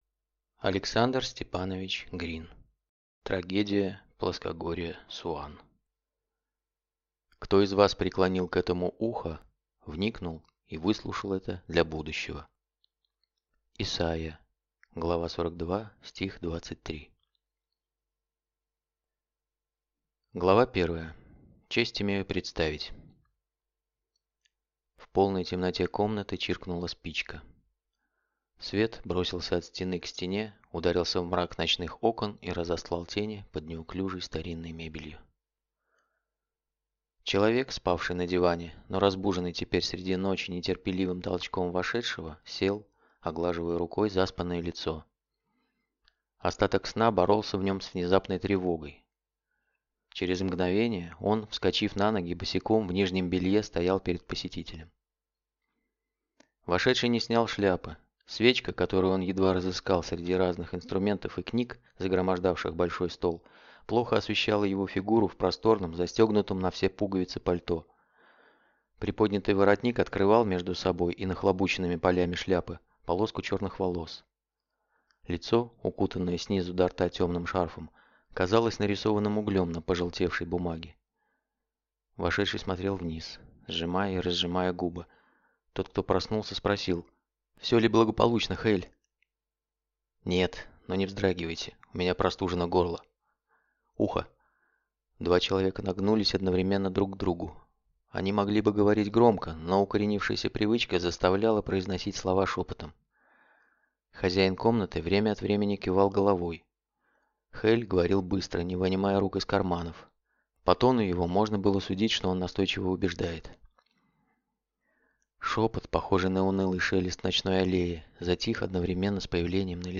Аудиокнига Трагедия плоскогорья Суан | Библиотека аудиокниг